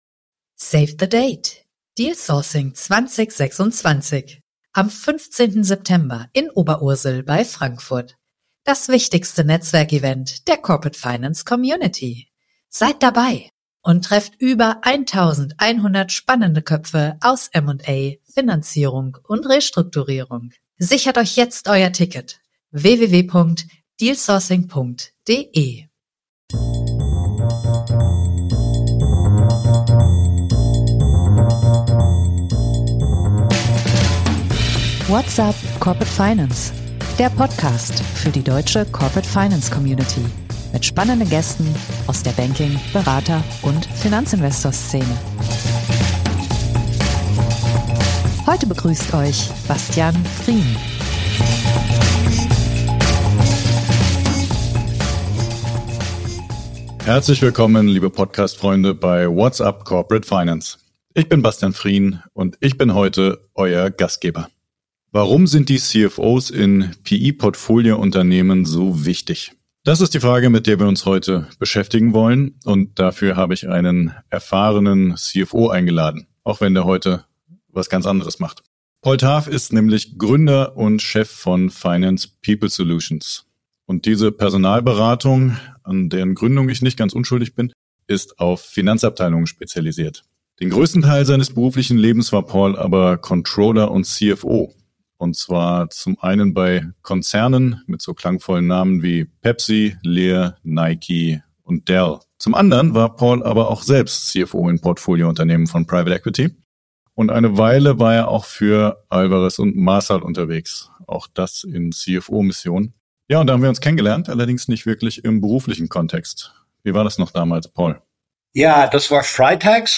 Der CFO ist in Portfoliounternehmen von PE-Unternehmen ein zentraler Faktor. Warum erleben wir trotzdem so viele Fehlbesetzungen, und was muss der ideale Kandidat können? Darüber sprechen wir in dieser Episode mit einem erfahrenen CFO und Personalberater.